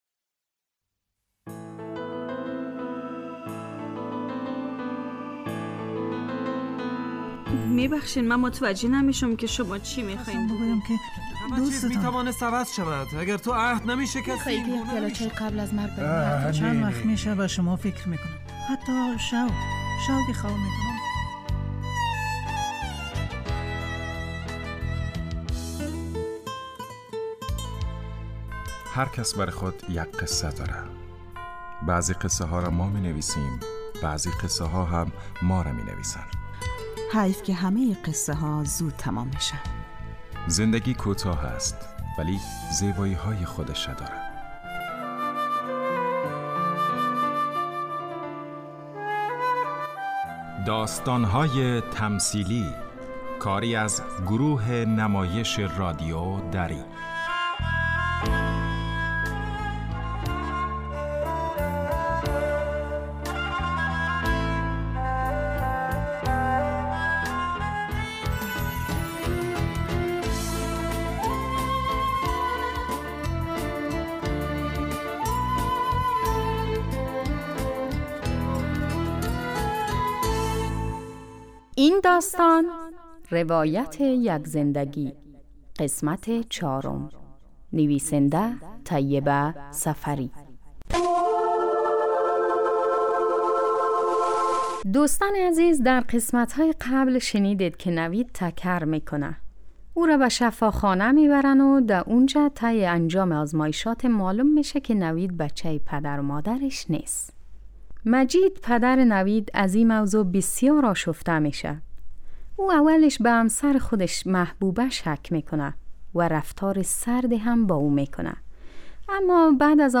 داستانهای تمثیلی نمایش 15 دقیقه ای هستند که هر روز ساعت 4:45 عصربه وقت وافغانستان پخش می شود.